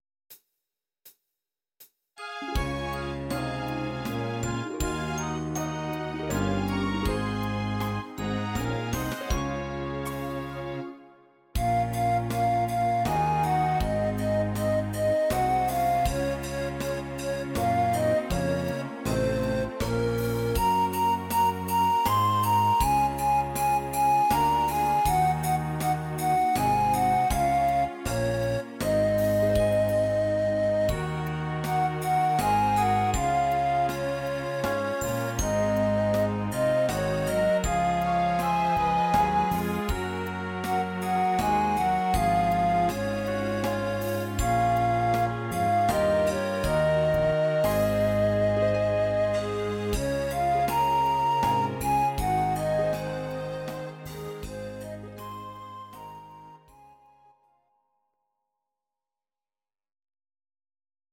Audio Recordings based on Midi-files
Oldies, German